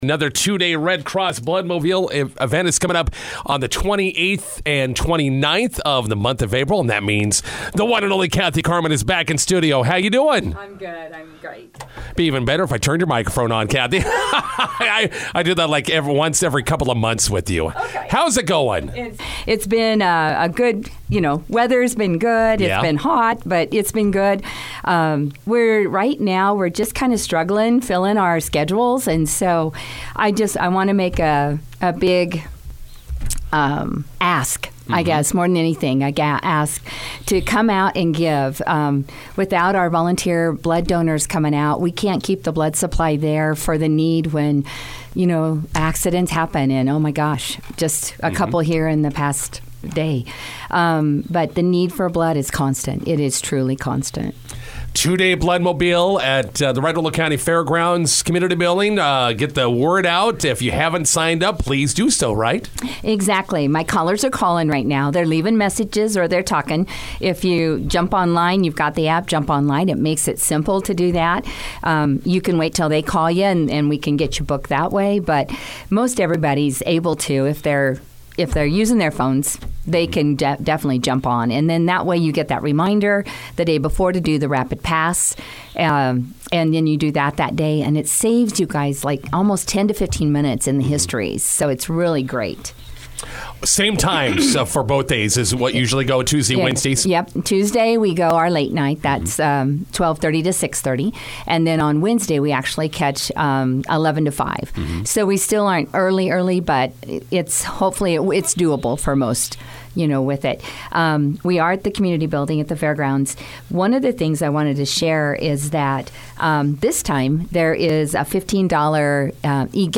INTERVIEW: Blood donors are needed for area bloodmobiles.